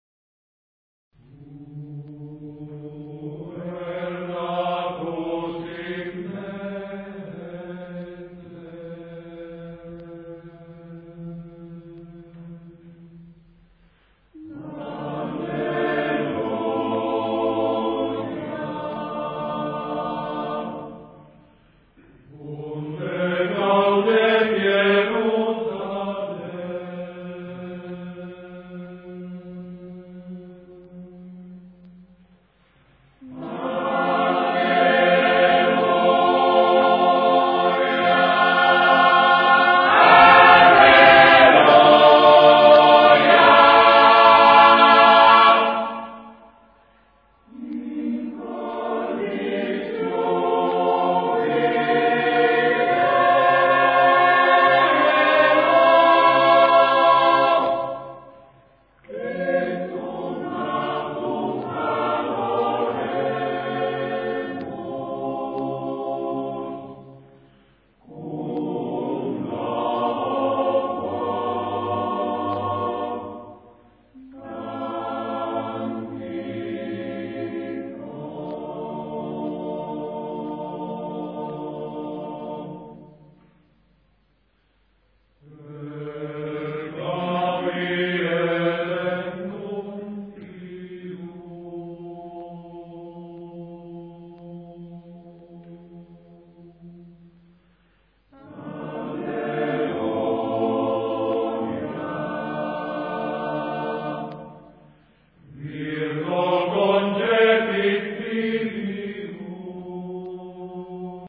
Puer Natus [ voci virili ] Puer natus in Bethlem alleluja unde gaudet Ierusalem alleluja alleluja in cordis jubilo.